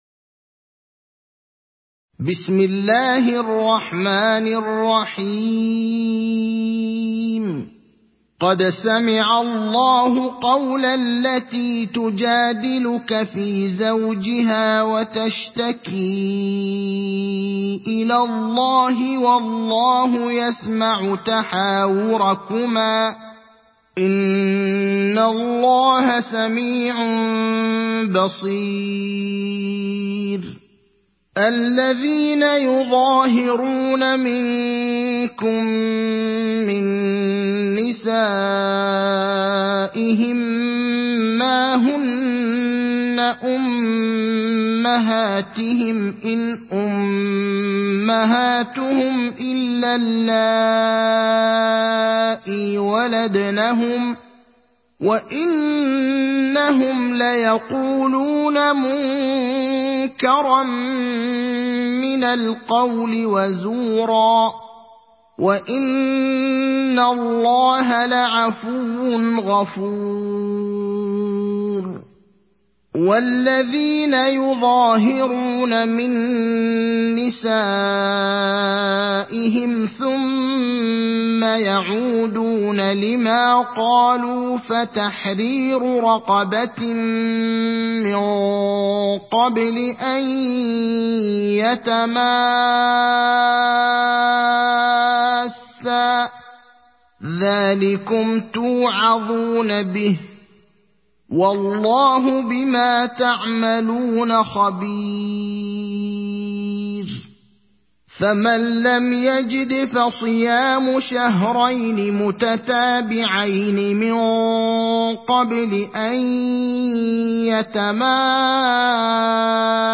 تحميل سورة المجادلة mp3 بصوت إبراهيم الأخضر برواية حفص عن عاصم, تحميل استماع القرآن الكريم على الجوال mp3 كاملا بروابط مباشرة وسريعة